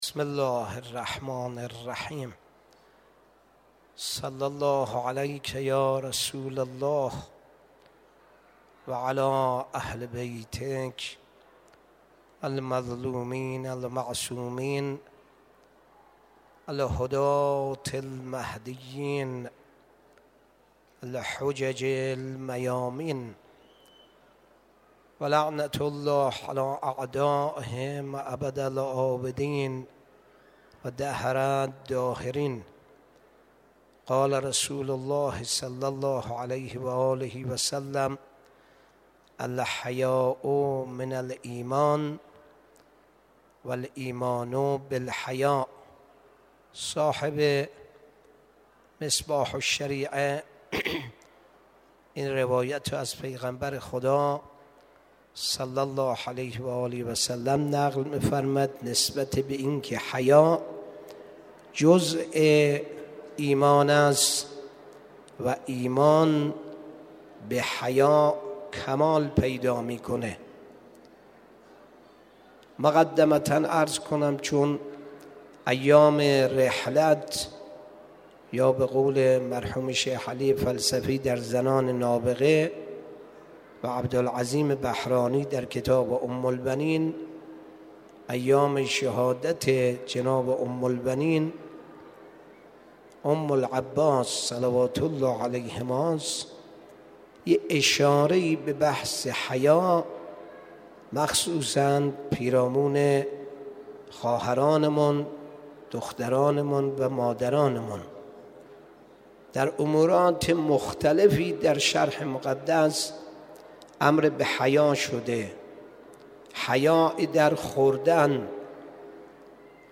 7 دی ماه 1402 حرم مطهر حضرت احمد بن موسی الکاظم علیه السلام